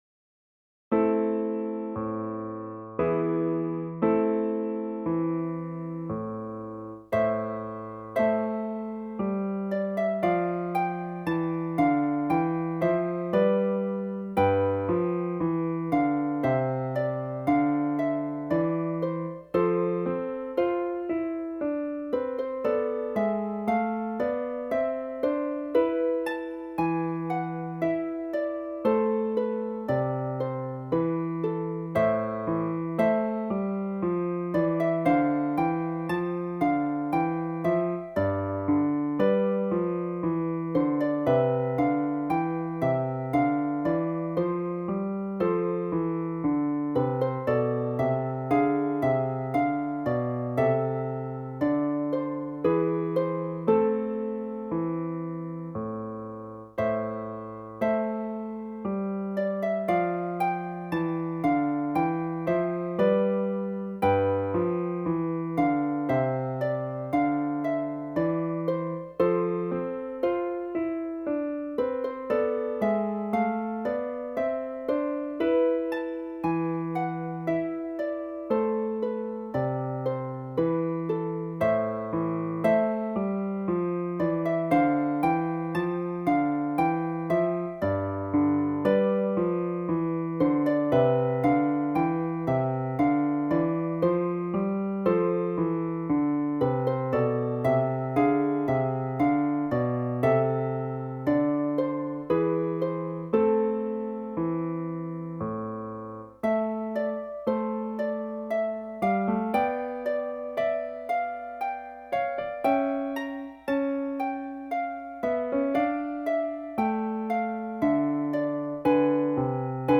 DIGITAL SHEET MUSIC - FLUTE with PIANO ACCOMPANIMENT
Flute Solo, Classical
piano with slower practice version and faster performance